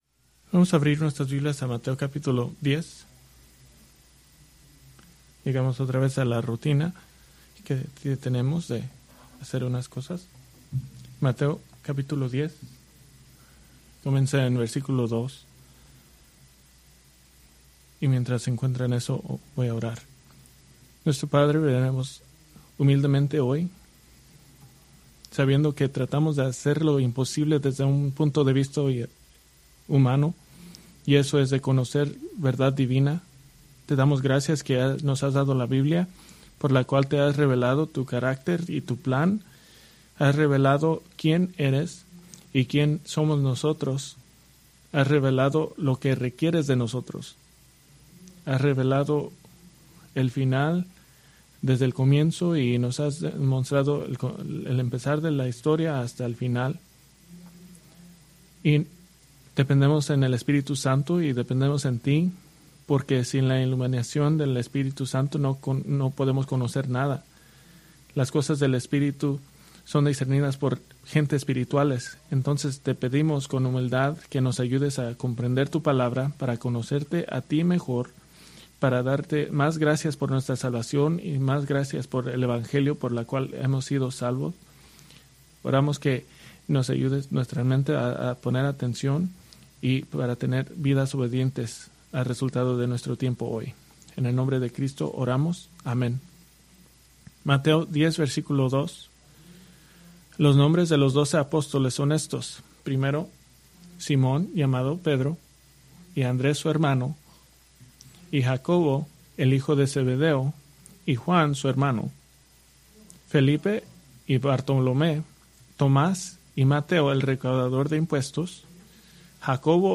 Preached March 23, 2025 from Mateo 10:3